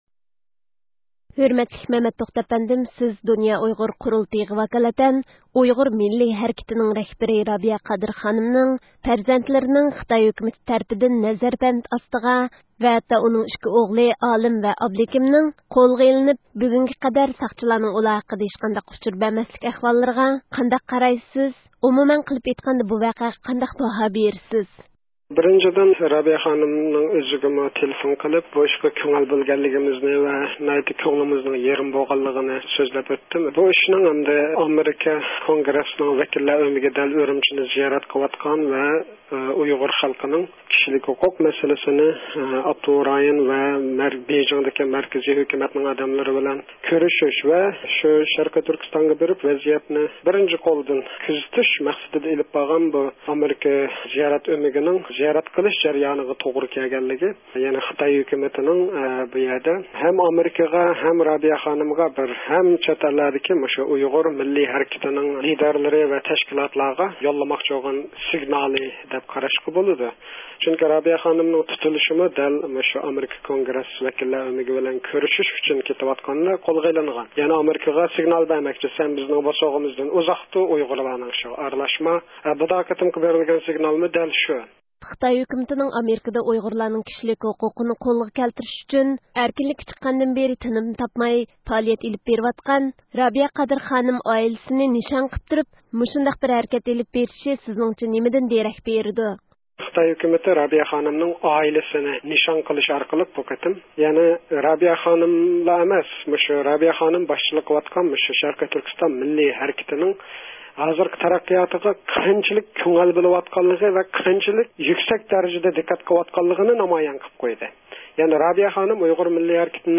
سۆھبەت ئېلىپ باردى.